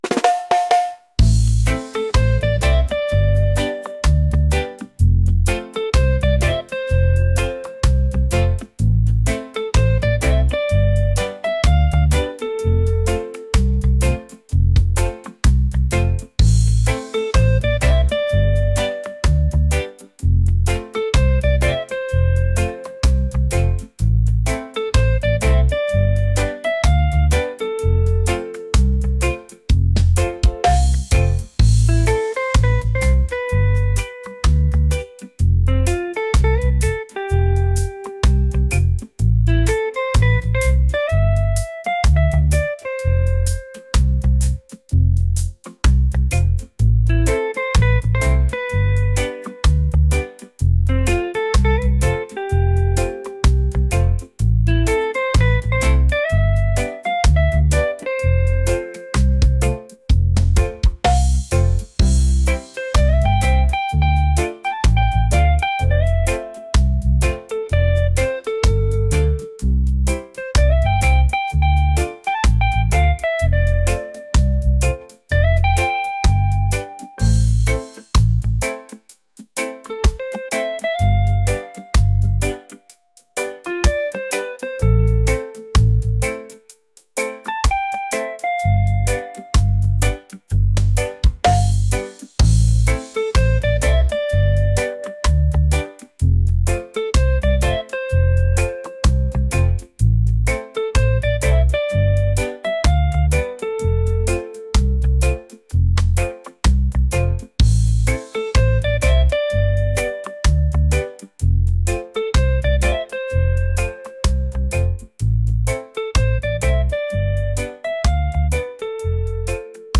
laid-back | reggae | smooth